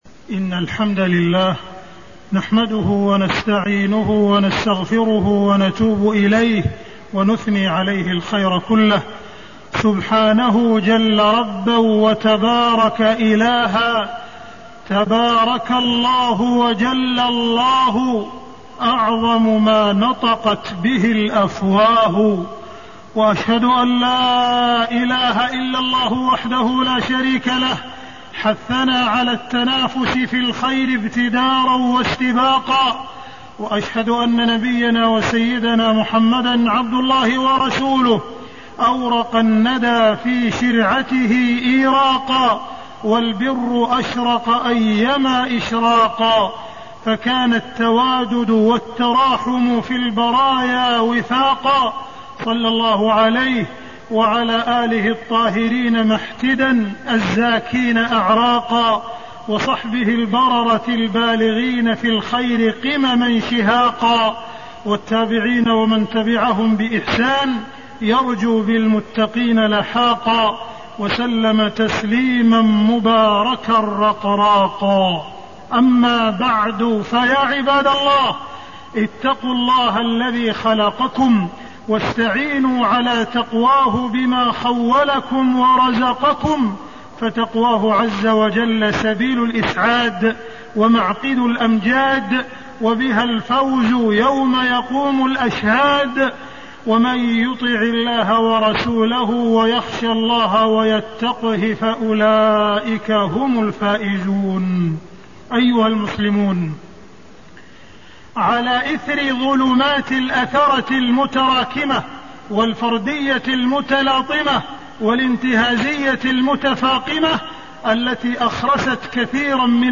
تاريخ النشر ٢٩ شوال ١٤٣١ هـ المكان: المسجد الحرام الشيخ: معالي الشيخ أ.د. عبدالرحمن بن عبدالعزيز السديس معالي الشيخ أ.د. عبدالرحمن بن عبدالعزيز السديس التنافس في فعل الخير